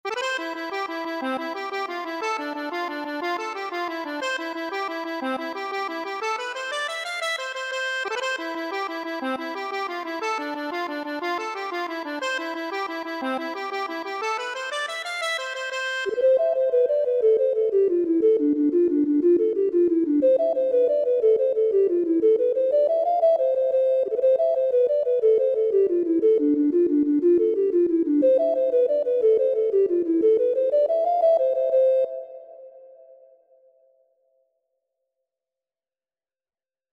Free Sheet music for Accordion
C major (Sounding Pitch) (View more C major Music for Accordion )
6/8 (View more 6/8 Music)
Accordion  (View more Intermediate Accordion Music)
Traditional (View more Traditional Accordion Music)